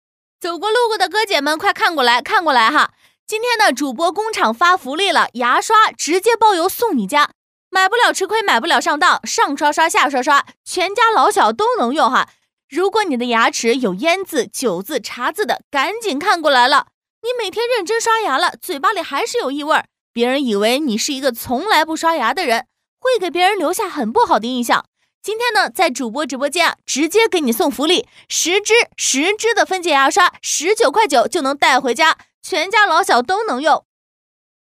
女C3-抖音直播【卖牙刷】-自然口语化
女C3-百变女王 素人自然
女C3-抖音直播【卖牙刷】-自然口语化.mp3